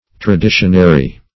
traditionary - definition of traditionary - synonyms, pronunciation, spelling from Free Dictionary
Traditionary \Tra*di"tion*a*ry\, a.